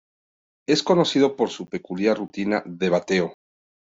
Read more peculiar Frequency C2 Hyphenated as pe‧cu‧liar Pronounced as (IPA) /pekuˈljaɾ/ Etymology Borrowed from Latin pecūliāris In summary Borrowed from Latin pecūliāris.